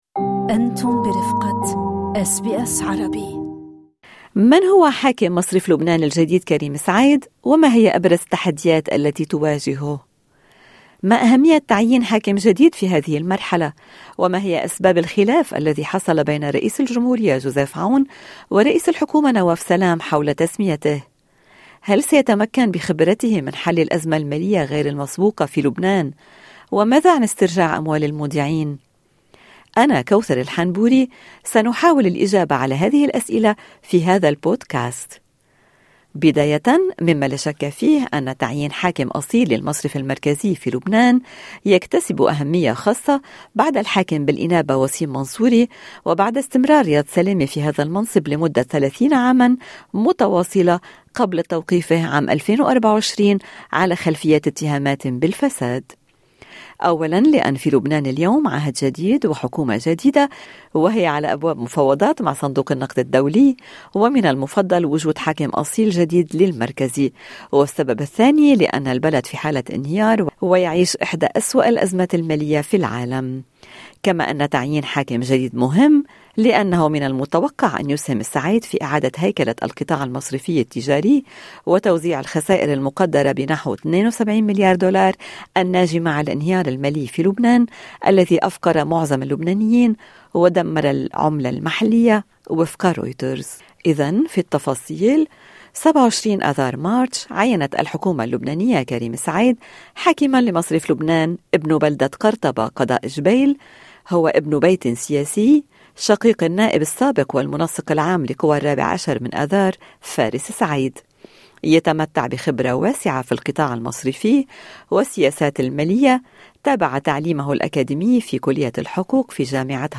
خبير اقتصادي يشرح